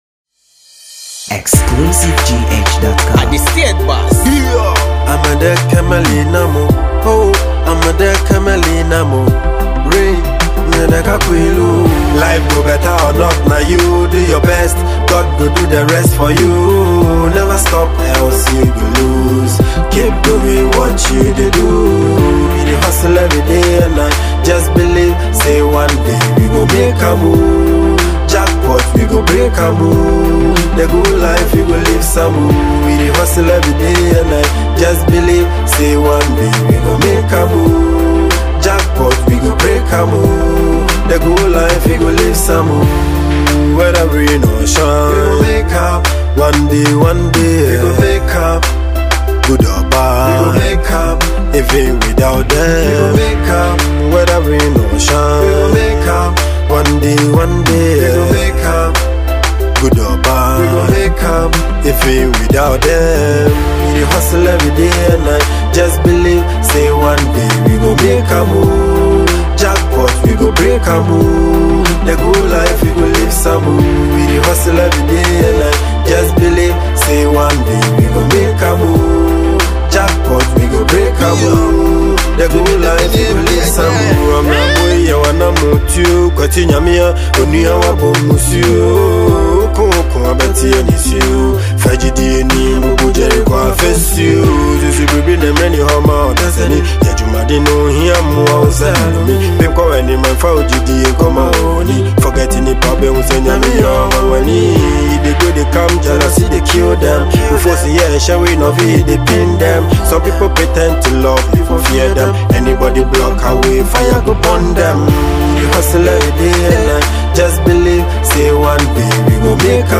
a motivational song